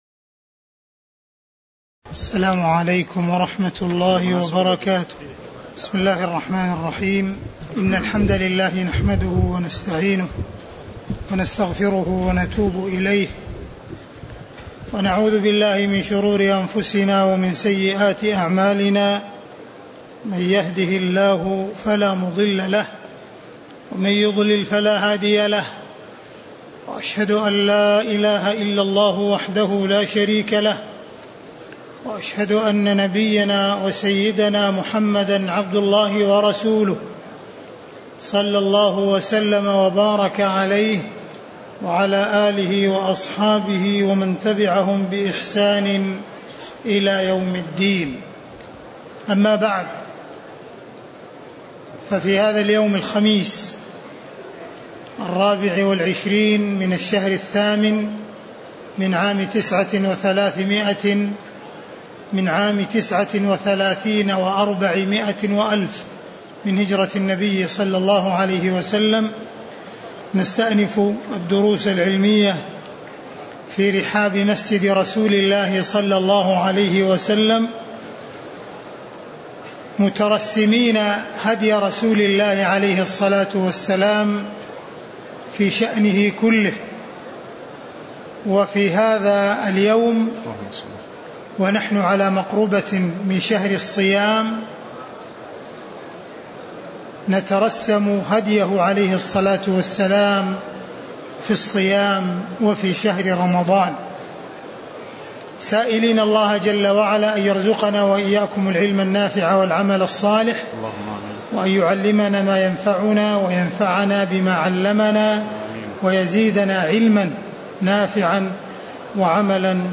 تاريخ النشر ٢٤ شعبان ١٤٣٩ هـ المكان: المسجد النبوي الشيخ: معالي الشيخ أ.د. عبدالرحمن بن عبدالعزيز السديس معالي الشيخ أ.د. عبدالرحمن بن عبدالعزيز السديس من قوله: فصل في هديه صلى الله عليه وسلم في الصيام (026)